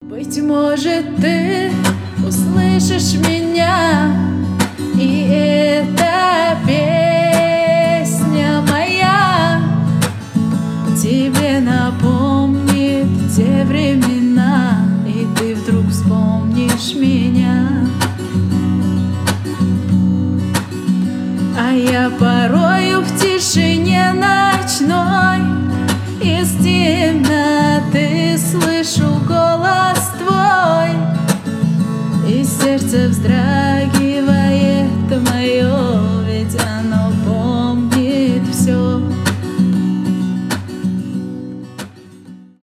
live
cover , акустика , красивый вокал , гитара , душевные